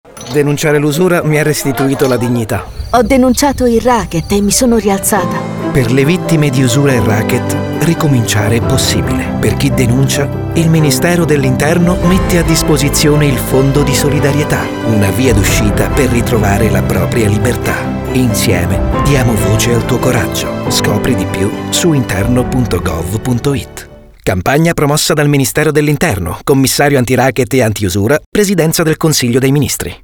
Lo spot radio